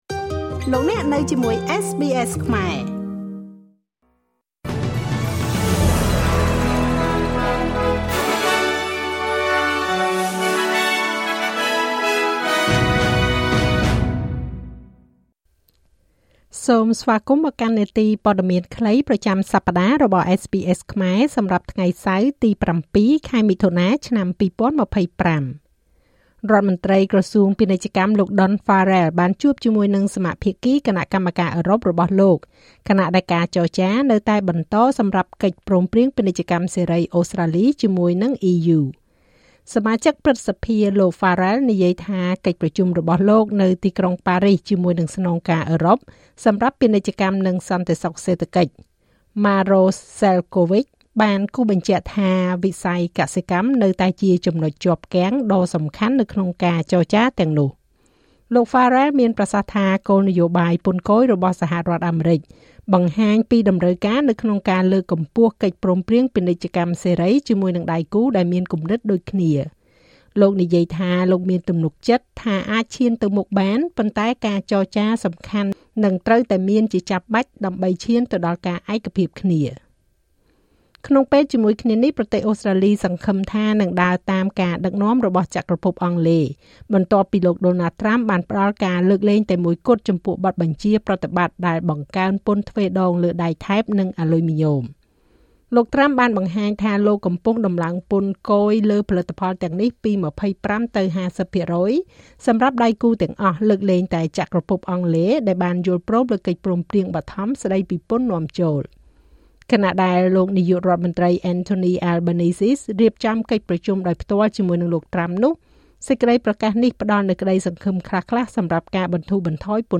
នាទីព័ត៌មានខ្លីប្រចាំសប្តាហ៍របស់SBSខ្មែរ សម្រាប់ថ្ងៃសៅរ៍ ទី៧ ខែមិថុនា ឆ្នាំ២០២៥